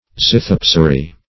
Search Result for " zythepsary" : The Collaborative International Dictionary of English v.0.48: Zythepsary \Zy*thep"sa*ry\ (z[i^]*th[e^]p"s[.a]*r[u^]), n. [Gr. zy^qos a kind of beer + 'e`psein to boil.]